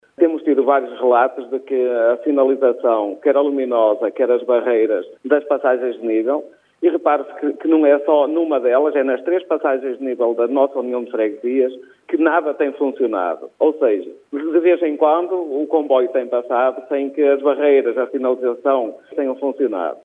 Está marcada para este sábado, de manhã, uma manifestação pela segurança nas passagens de nível em Quintiães e Aguiar. O presidente da União de Freguesias, António Pereira, explica à Rádio Barcelos o que motiva este protesto: